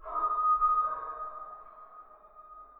scpcb-godot/SFX/Ambient/Forest/ambient7.ogg at e4012f8b7335c067e70d16efd9e1b39f61021ea4